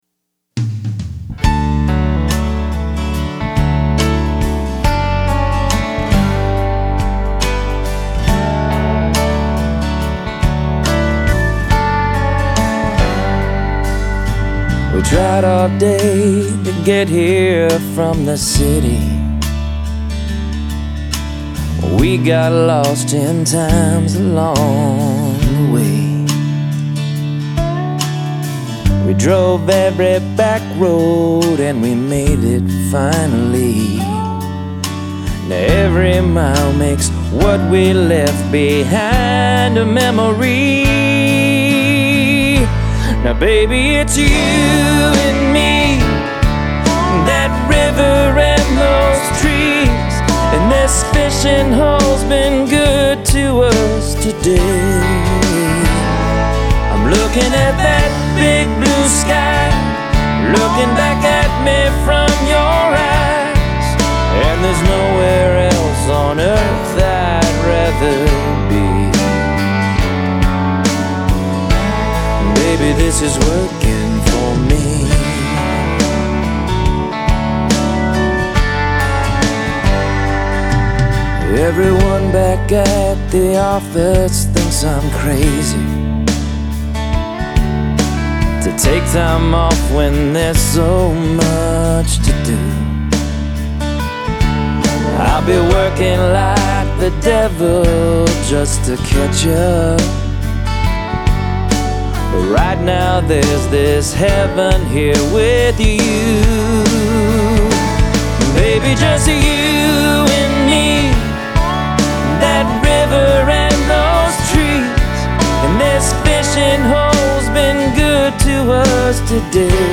Recorded at Beaird Music Group, Nashville TN 2007